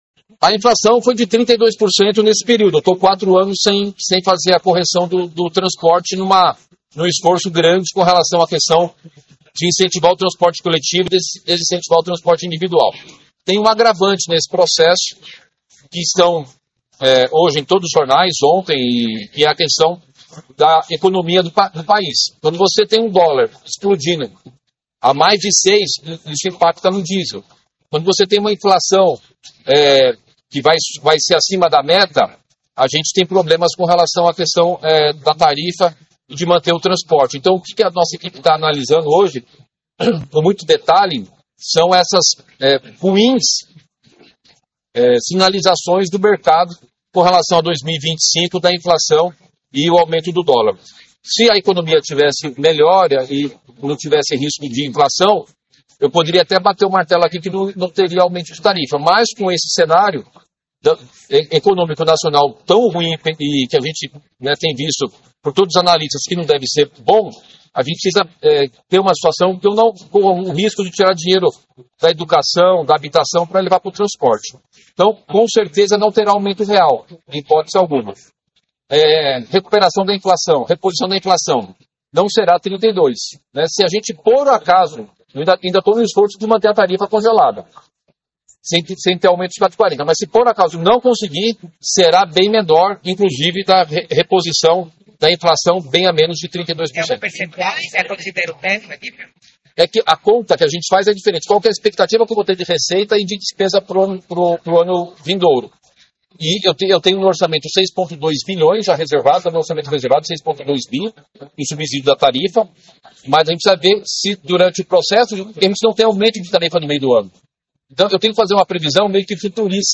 É explodir a inflação e a gente colapsar o sistema de transporte”* – disse em entrevista coletiva.